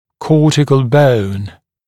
[‘kɔːtɪkl bəun][‘ко:тикл боун]кортикальный слой кости